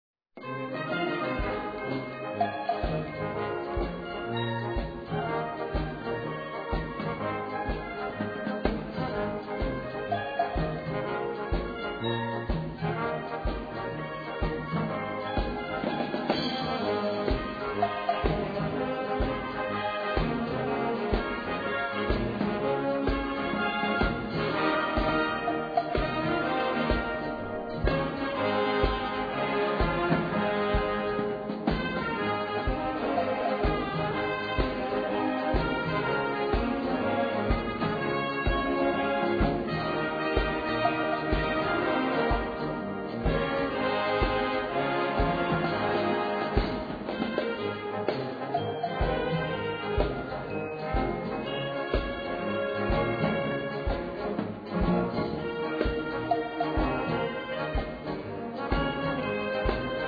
Gattung: Raggae
Besetzung: Blasorchester
Der berühmte Walzer als Raggae für Blasorchester arrangiert.